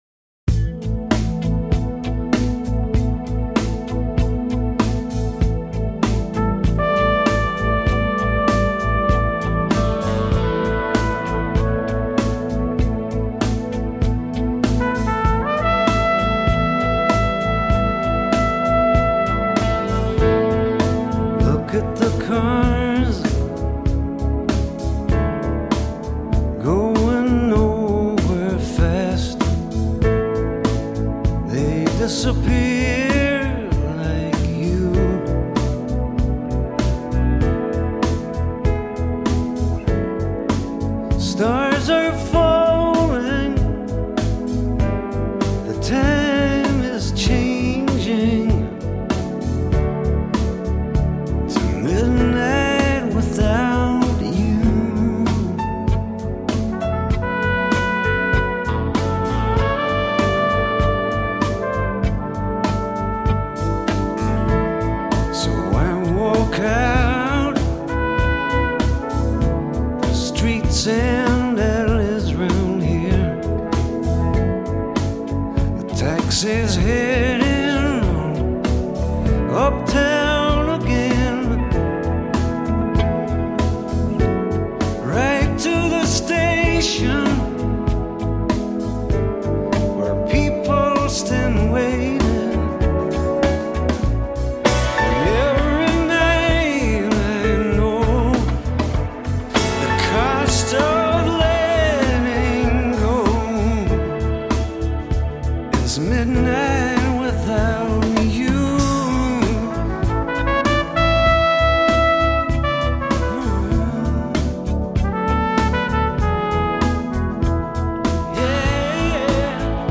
爵士小号手
小号演奏